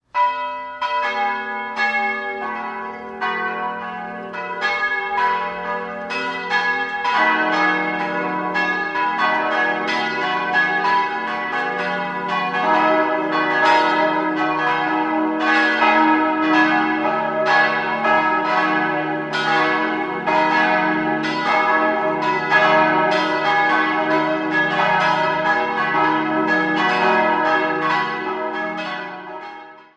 1977 konnte ein neuer, größerer Kirchenraum neben dem alten Gotteshaus eingeweiht werden. Idealquartett: dis'-fis'-gis'-h' Salvatorglocke dis' 1950 Ludwig Will, Bruckberg Marienglocke fis' 1950 Ludwig Will, Bruckberg Glocke 3 gis' 1839 Ferdinand Pascolini, Ingolstadt Glocke 4 h' 1664 Caspar Haslauer, Ingolstadt